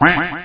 Then I put Arnold in an echo chamber.
Sure sounds like an echo to me.
quack1a.wav